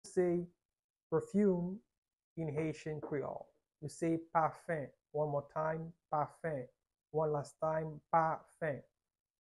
How to say "Perfume" in Haitian Creole - "Pafen" pronunciation by a native Haitian teacher
“Pafen” Pronunciation in Haitian Creole by a native Haitian can be heard in the audio here or in the video below:
How-to-say-Perfume-in-Haitian-Creole-Pafen-pronunciation-by-a-native-Haitian-teacher.mp3